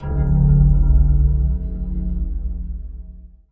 描述：深，打，共鸣